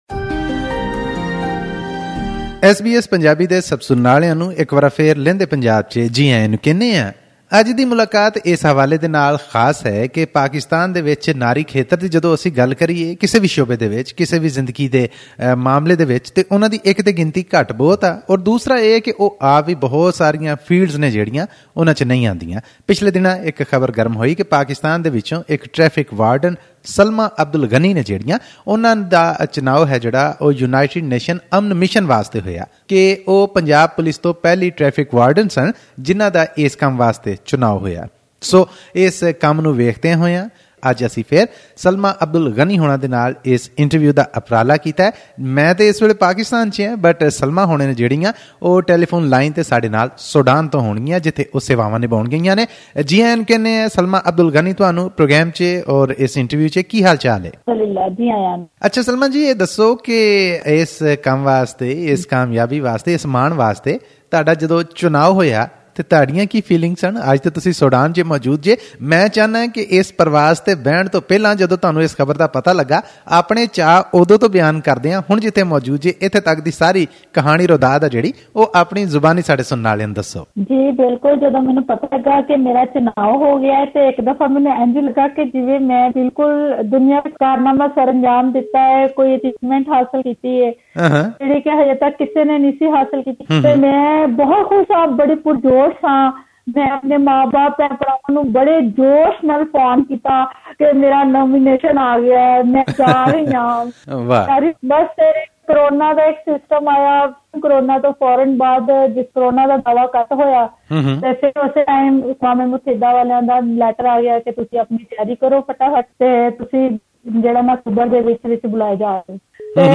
Source: Supplied Click the audio icon on the photo above to listen to the full conversation in Punjabi.